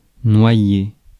Ääntäminen
IPA : /draʊn/ US : IPA : [draʊn]